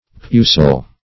Search Result for " pusil" : The Collaborative International Dictionary of English v.0.48: Pusil \Pu"sil\, a. [L. pusillus very little.]